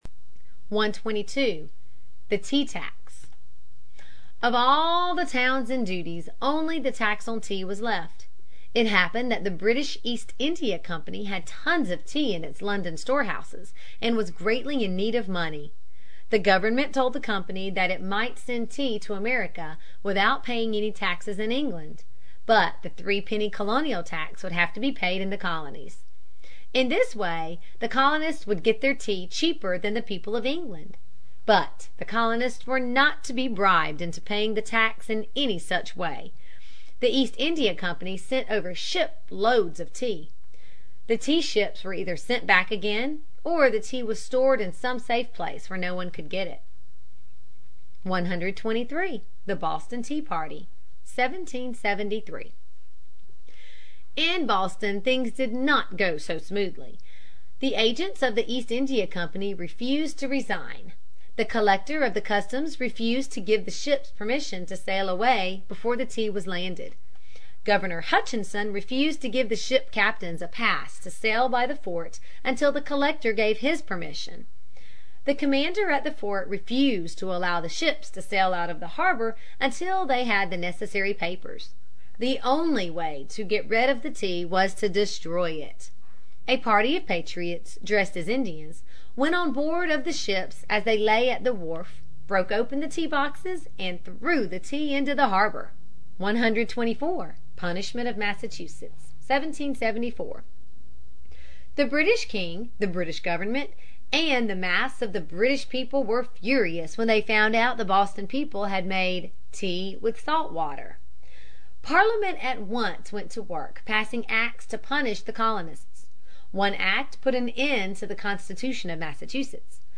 在线英语听力室美国学生历史 第40期:革命的的到来(2)的听力文件下载,这套书是一本很好的英语读本，采用双语形式，配合英文朗读，对提升英语水平一定更有帮助。